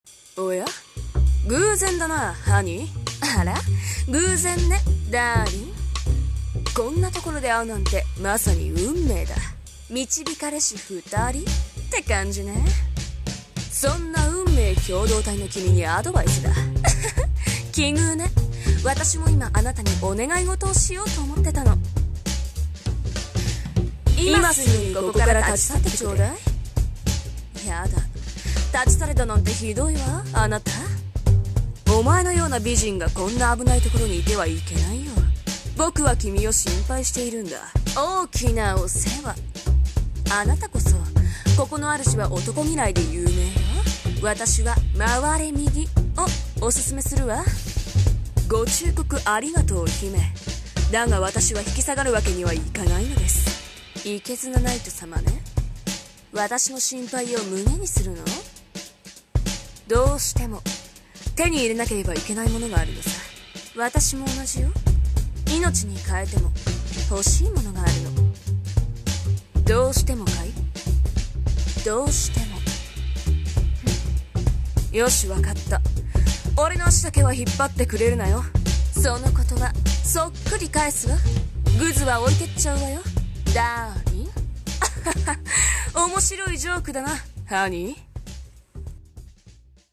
【声劇】潜入
Jazz Style Rythm Track